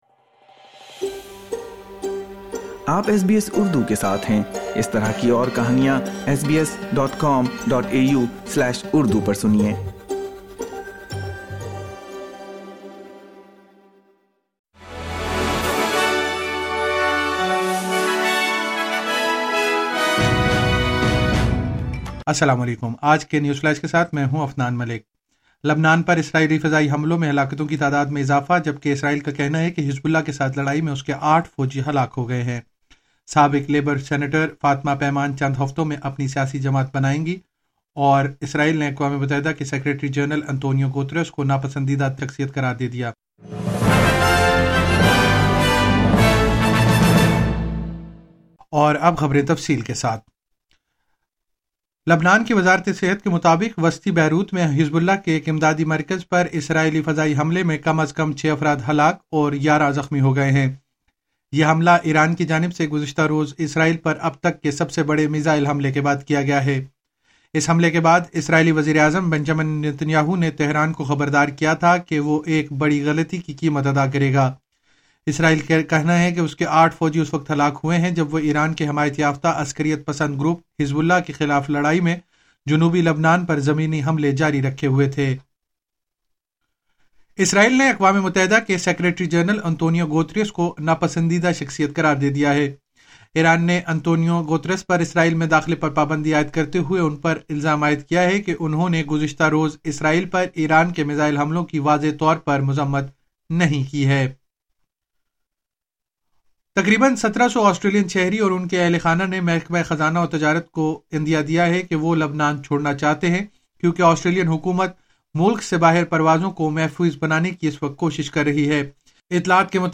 نیوز فلیش 03 اکتوبر 2024: اسرائیل کا کہنا ہے کہ حزب اللہ کے ساتھ لڑائی میں اس کے آٹھ فوجی ہلاک ہوئے ہیں